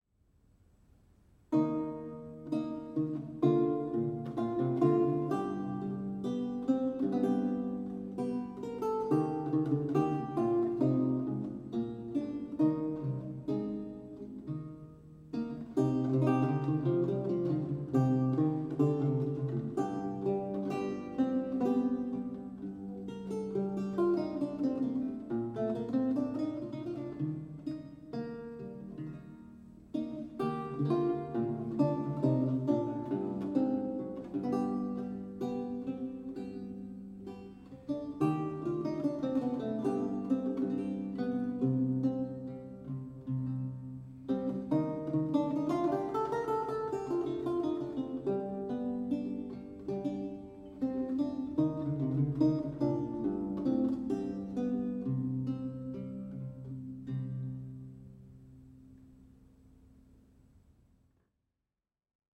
Audio recording of a lute piece from the E-LAUTE project